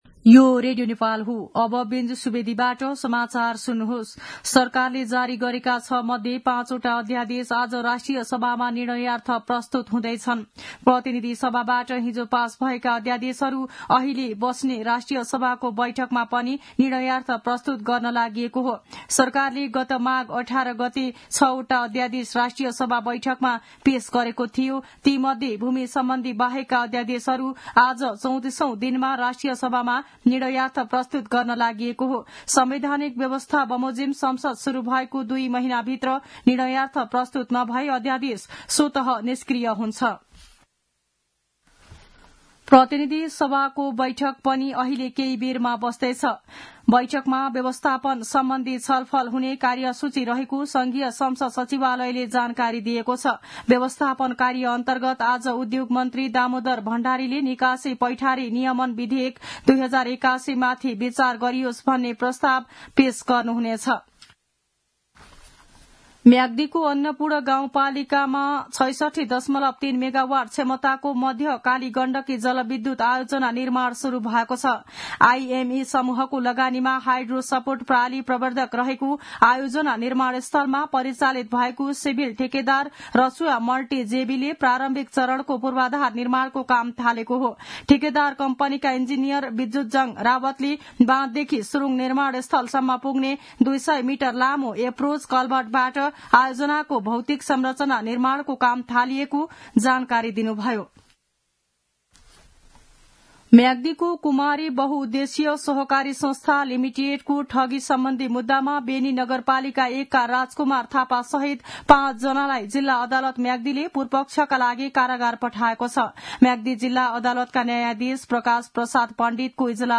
दिउँसो १ बजेको नेपाली समाचार : २३ फागुन , २०८१
1-pm-nepali-news-.mp3